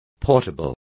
Complete with pronunciation of the translation of portable.